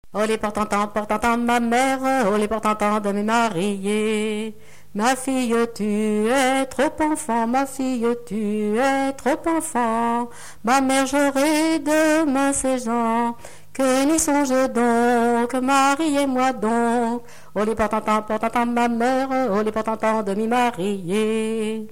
Genre énumérative
Pièce musicale inédite